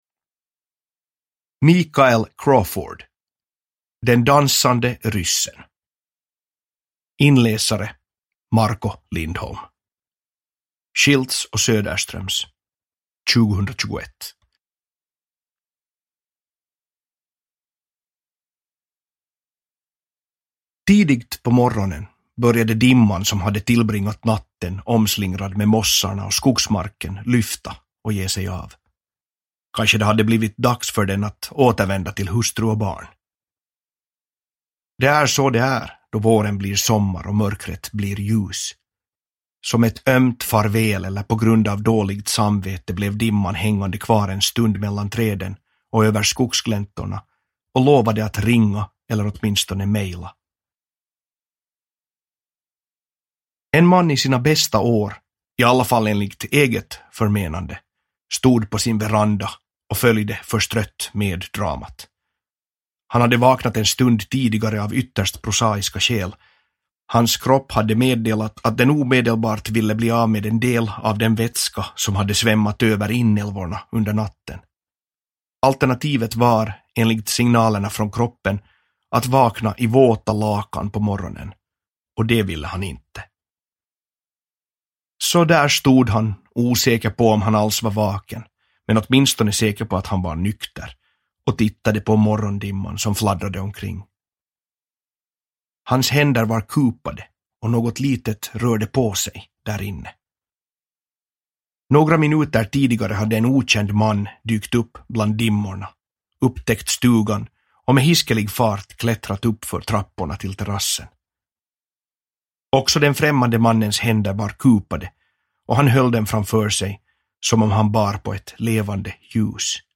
Den dansande ryssen – Ljudbok – Laddas ner